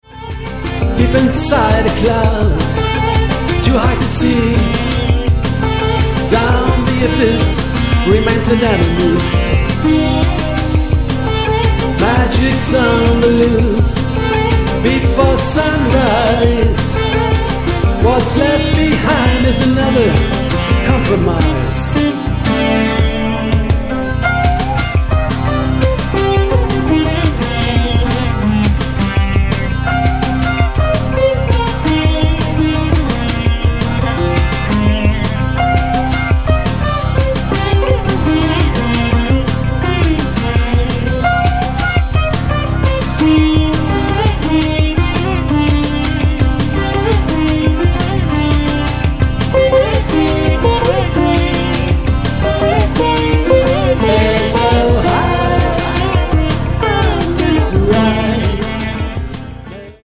sitar, guitar, vocals
bass
drums